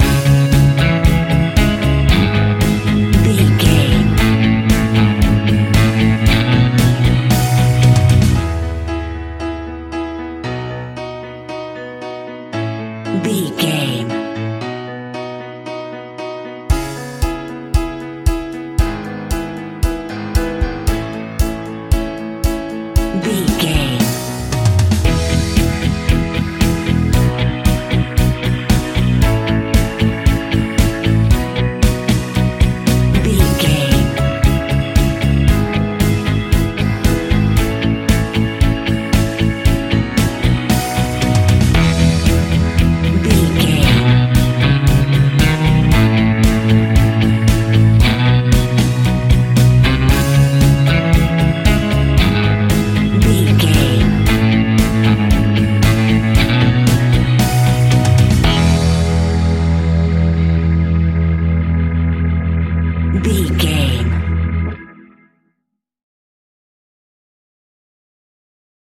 Epic / Action
Fast paced
Ionian/Major
pop rock
indie pop
fun
energetic
uplifting
acoustic guitars
drums
bass guitar
electric guitar
piano
organ